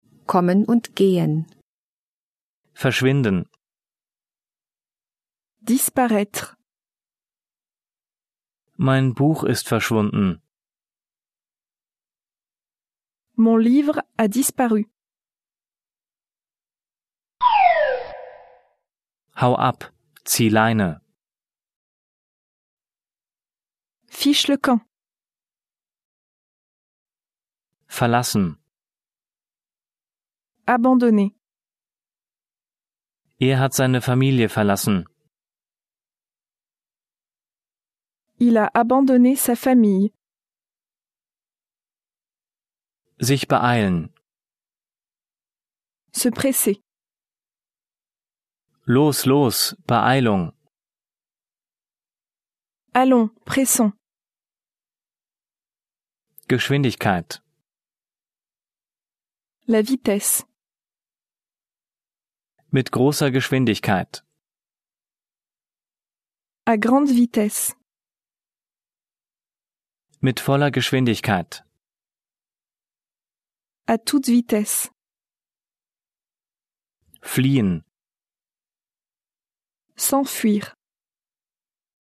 mit Übersetzungs- und Nachsprechpausen
Er ist zweisprachig aufgebaut (Deutsch - Französisch), nach Themen geordnet und von Muttersprachlern gesprochen.